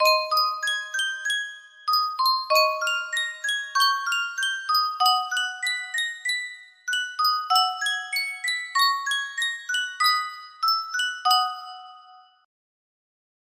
Sankyo Music Box - JR-SH1-2 KHH music box melody
Full range 60